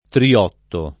triotto [ tri- 0 tto ]